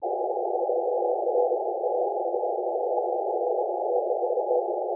Unfortunately he was too weak for a QSO, i.e. he didn’t hear me, but then it was still quite fascinating and showing again the magic of 10 m (although 6 m is the official “Magic Band”, hi).